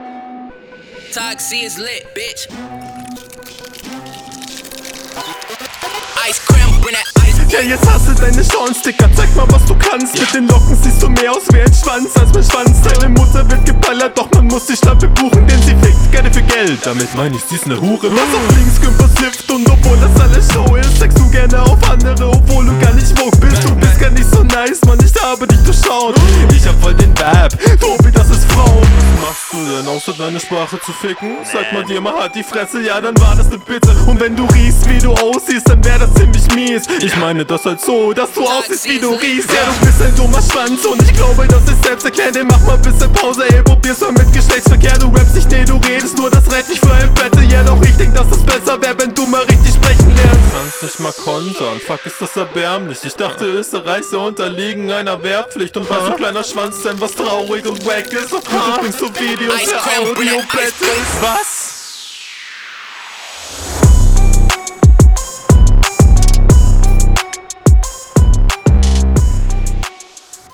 Der Flow ist schon stabil, Technik also Reimsetzung ist auch cool.